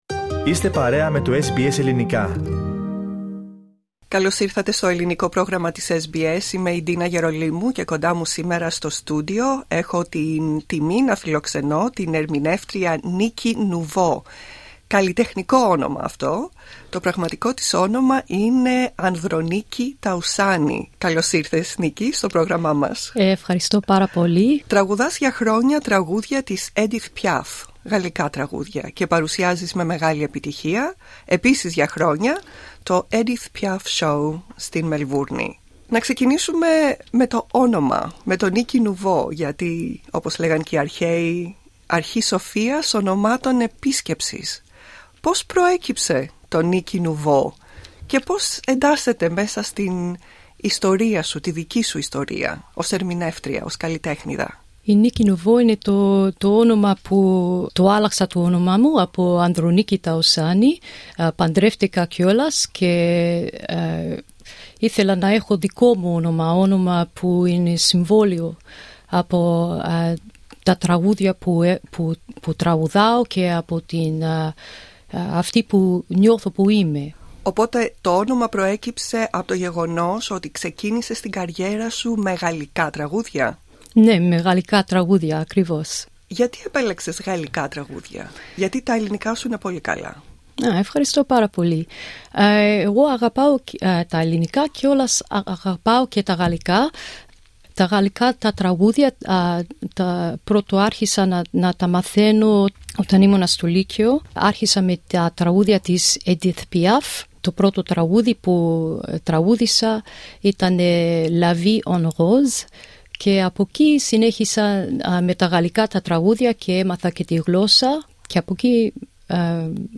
Περισσότερα ακούστε στην συνέντευξη που μας παραχώρησε.